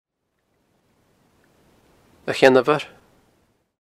Pronounced with a svarabhakti, i.e. Uh-chyen-a-vur.